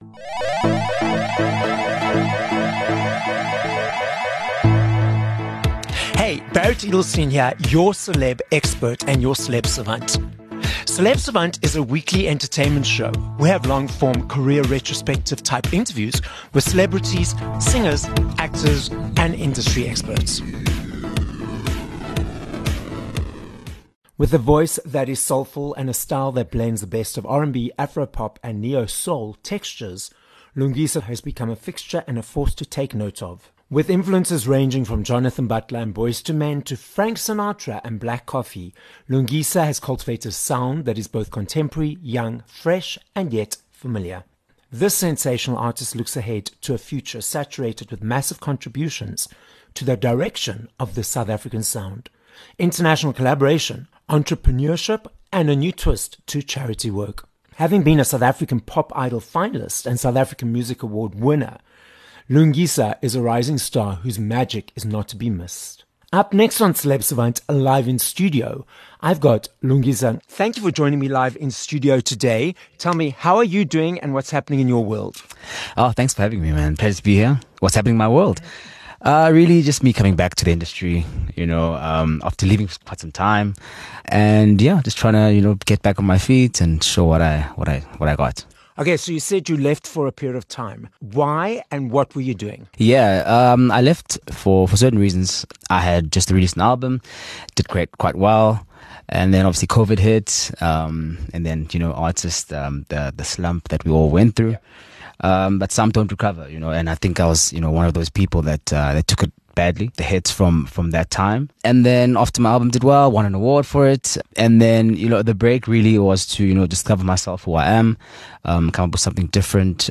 Interview
live in studio.